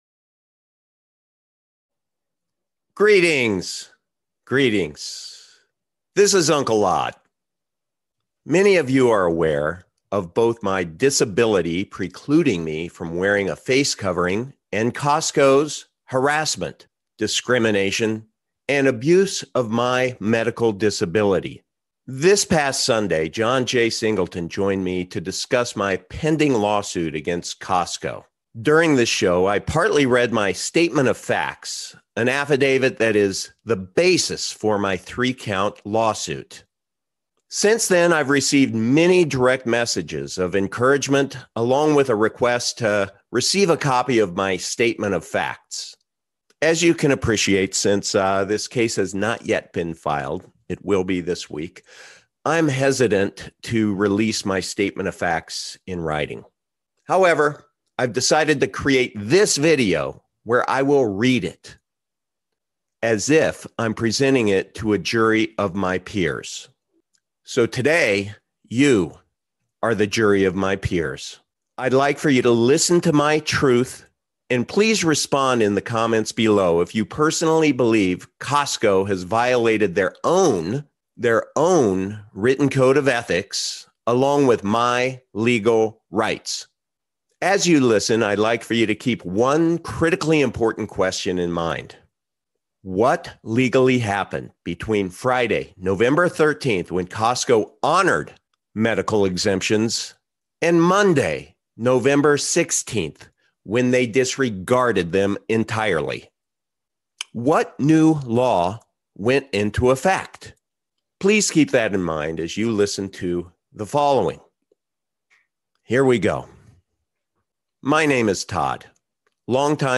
I’ll be filing my lawsuit against Co$tco this week. This video is a recording of my sworn affidavit.